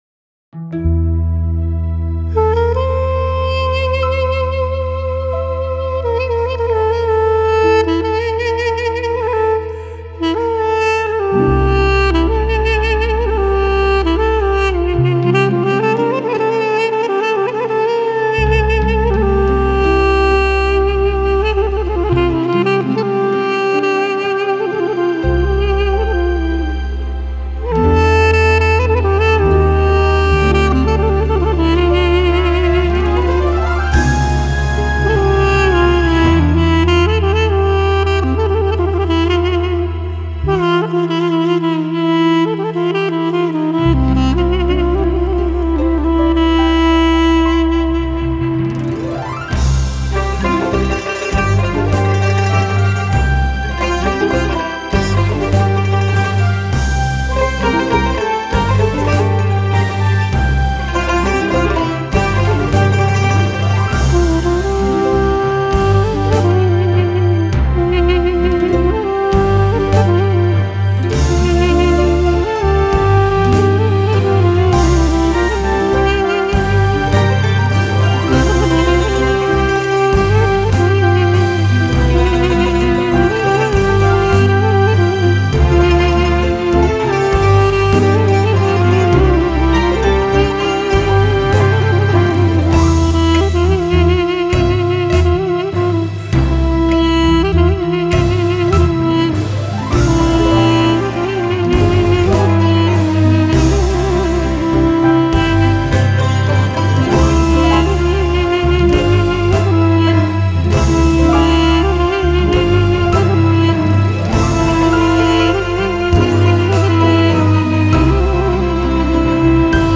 موسیقی بی کلام ملل